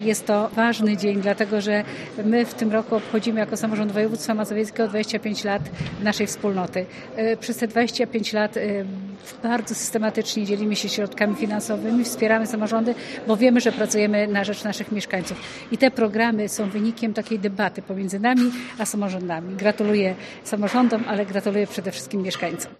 Członkini zarządu województwa mazowieckiego, Elżbieta Lanc dodaje, że to ważny dzień dla regionu: